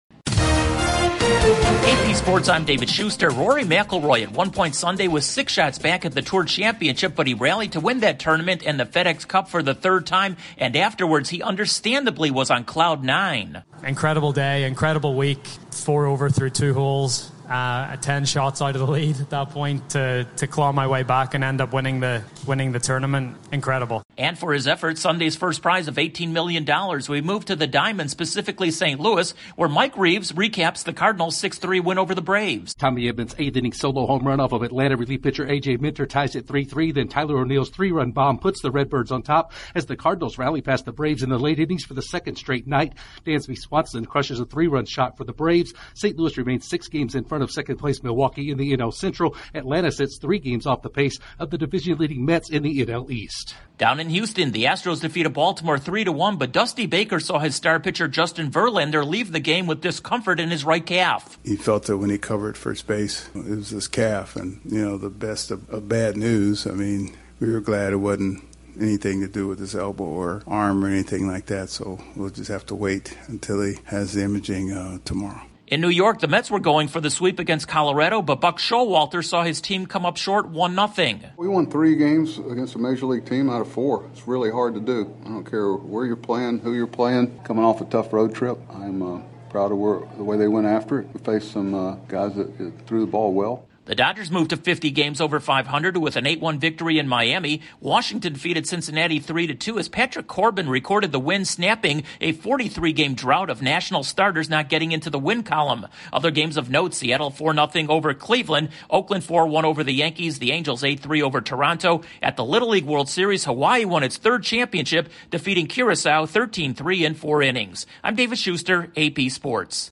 Rory McIlroy wins another FedEx Cup title, the Cardinals double up the Braves, the Astros see their top hurler make an early exit, the Mets fail to sweep the Rockies, the Dodgers keep winning and Hawaii claims Little League bragging rights. Correspondent